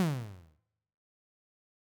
shoot_2.wav